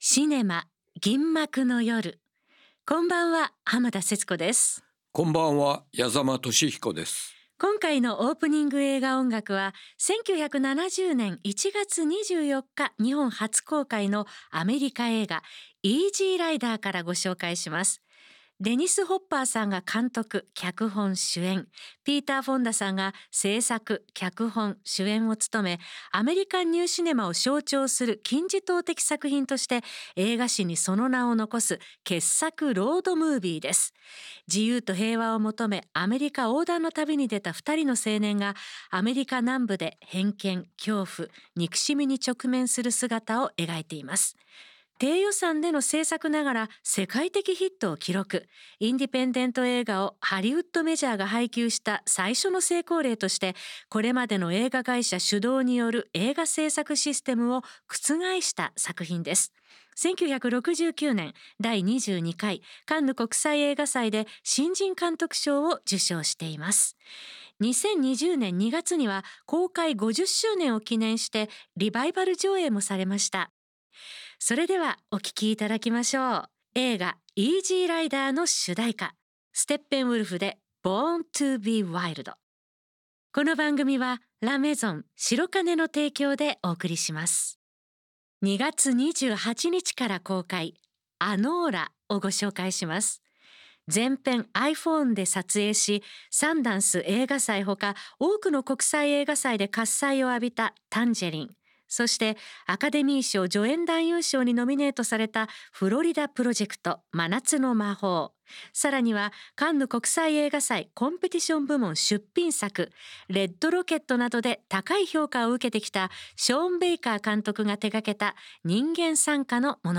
最新の映画情報と過去の名作映画を音楽と共に紹介する30分。